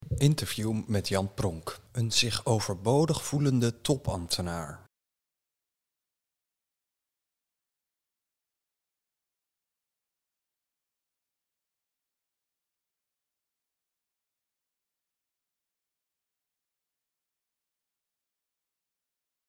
Interview met Jan Pronk